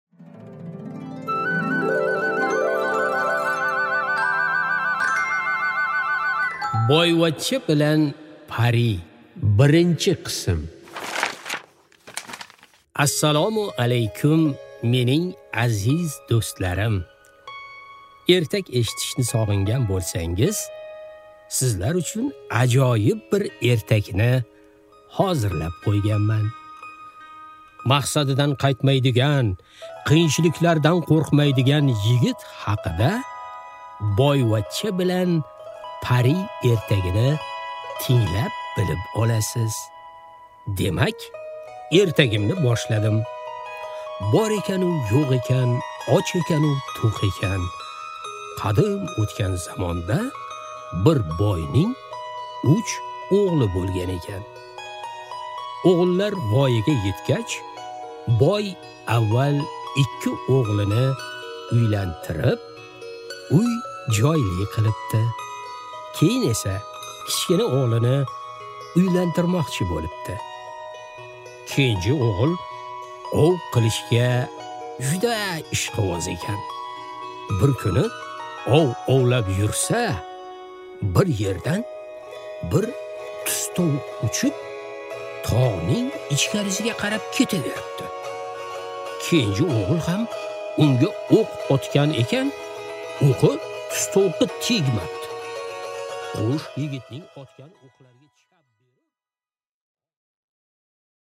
Аудиокнига Boyvachcha bilan pari | Библиотека аудиокниг